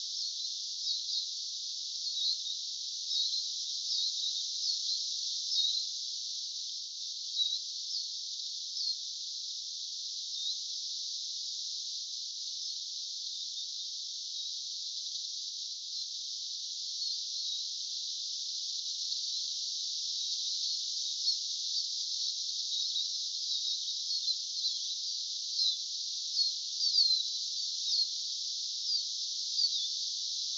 tuollaista vihervarpusen ääntelyä
tuollaista_vihervarpusen_aantelya.mp3